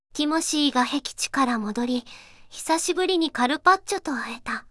voicevox-voice-corpus / ROHAN-corpus /四国めたん_セクシー /ROHAN4600_0017.wav